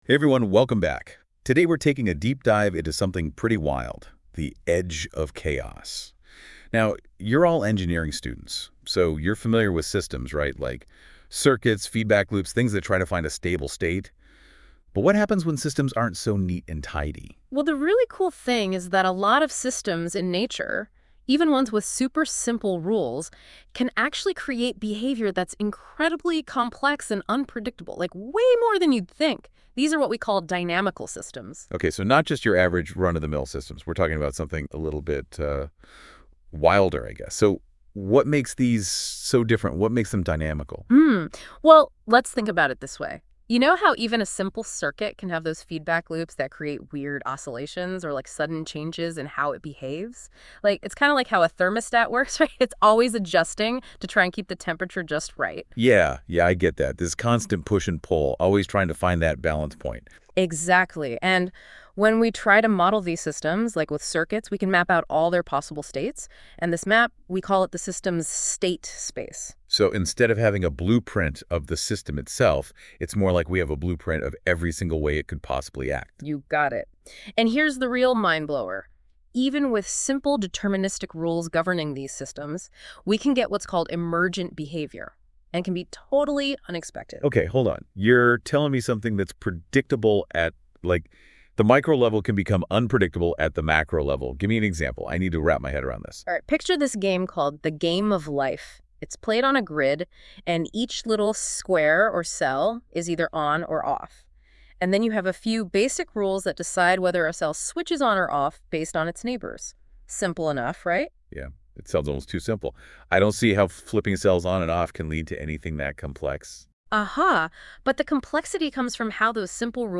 A companion AI-Generated podcast via Google NotebookLM is also available for fun (but it does not substitute the lecture notes at all! it brings up many connections not really in the argument, it is provided for fun only.)